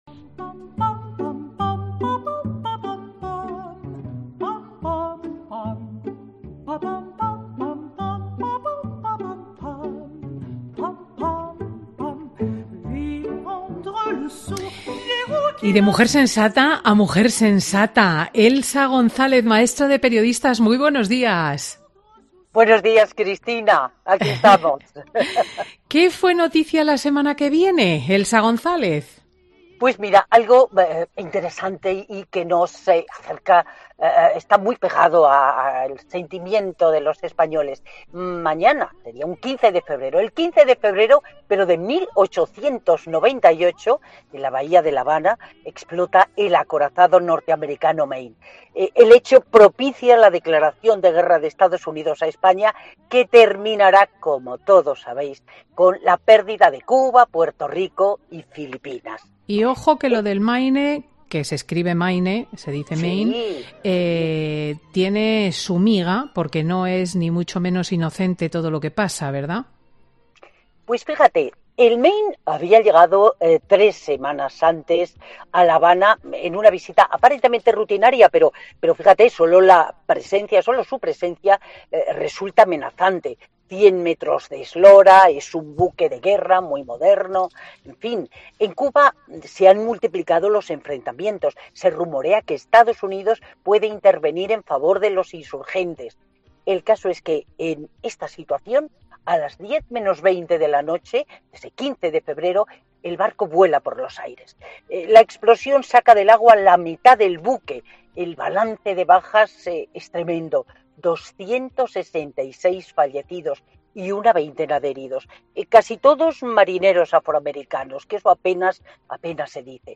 AUDIO: Elsa González, maestra de periodistas, cuenta en Fin de Semana con Cristina qué pasó por estas fechas pero hace ya unos cuantos años